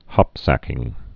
(hŏpsăkĭng) also hop·sack (-săk)